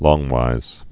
(lôngwīz, lŏng-)